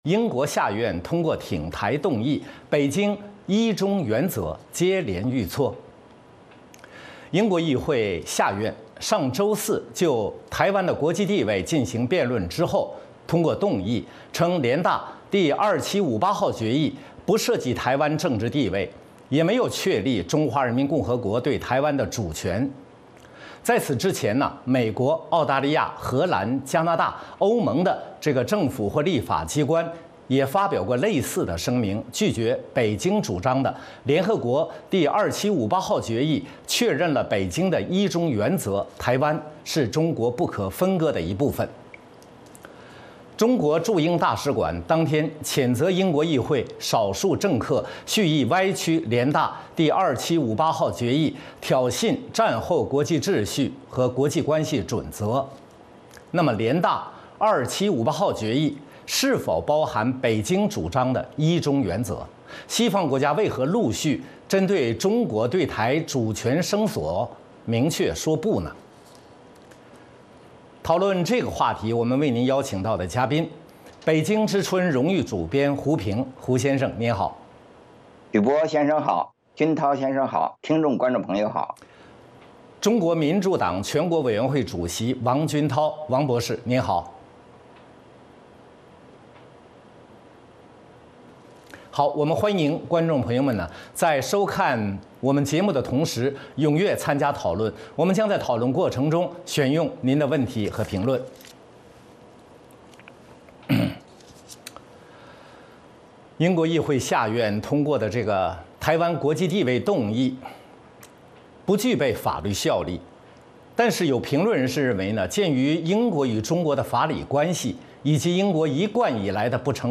《时事大家谈》围绕重大事件、热点问题、区域冲突以及中国内政外交的重要方面，邀请专家和听众、观众进行现场对话和讨论，利用这个平台自由交换看法，探索事实。